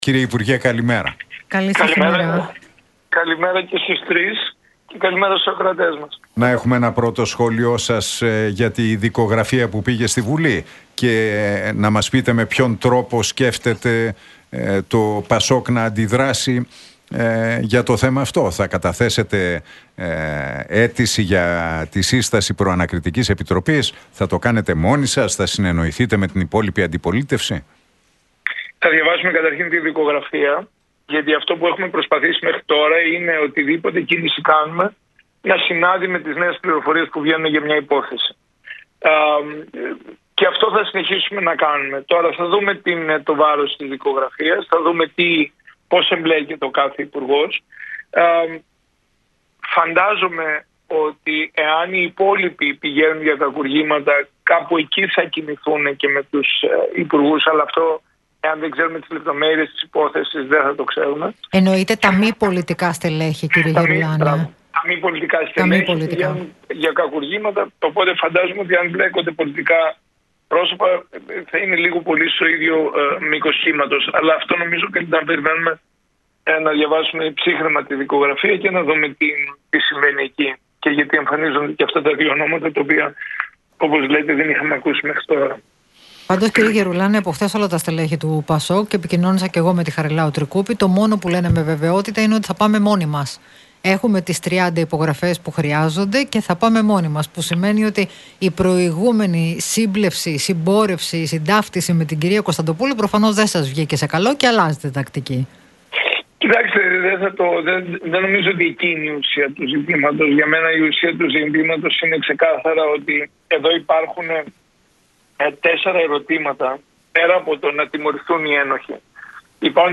Γερουλάνος στον Realfm 97,8 για τις δημοσκοπήσεις: Όσο ο θυμός είναι στο «κόκκινο», τα κόμματα που έχουν κυβερνήσει βρίσκονται σε άμυνα